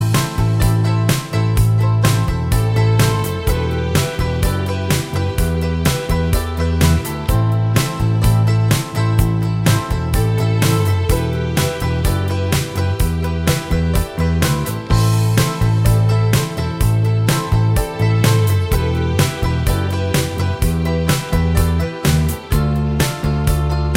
Minus Acoustic Guitar Indie / Alternative 4:27 Buy £1.50